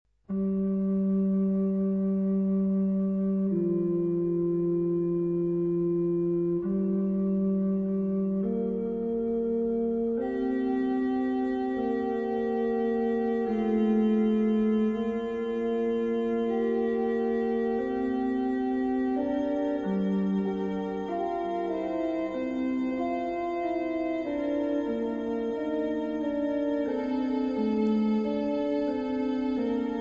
Personaggi e interpreti: organo ; Leonhardt, Gustav